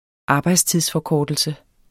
Udtale [ ˈɑːbɑjdstiðs- ]